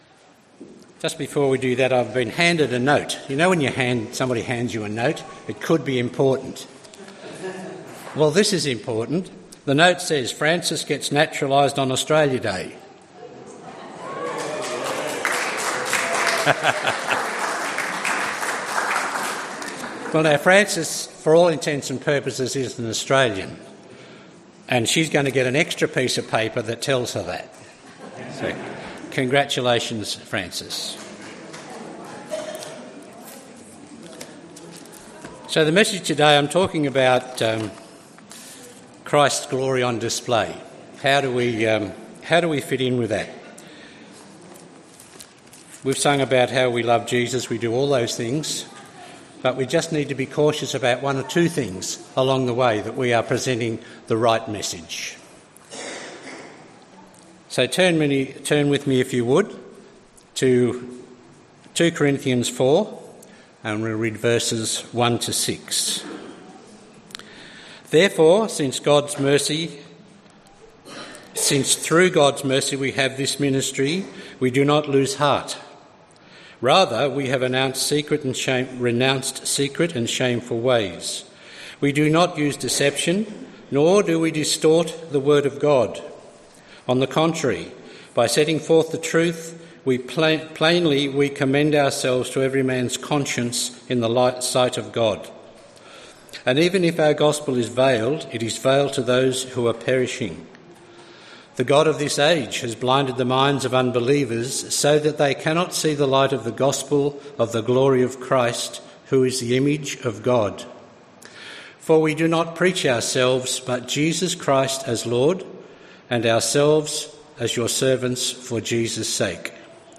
Bible reading and message from the 10AM meeting at Newcastle Worship and Community Centre of The Salvation Army. The bible reading is taken from 2 Corinthians 4:1-6.